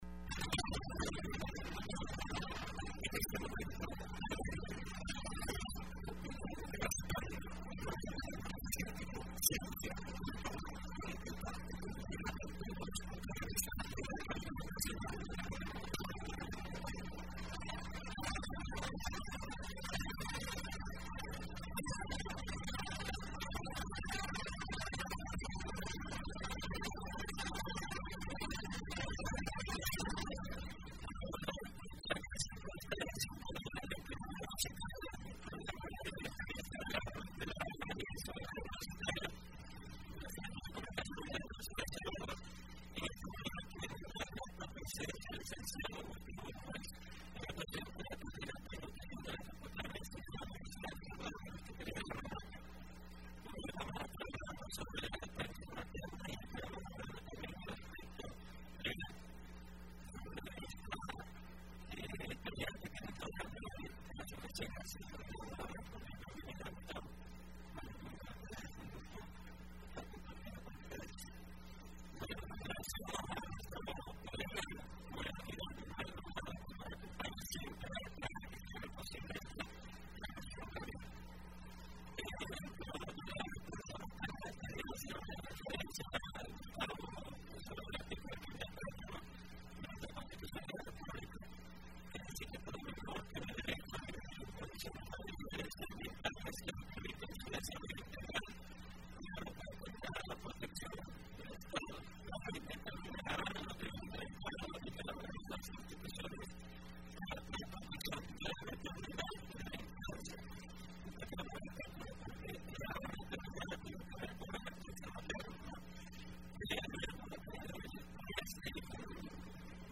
Entrevista Opinión Universitaria (25 febrero 2015): Importancia de la lactancia materna.